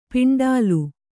♪ piṇḍālu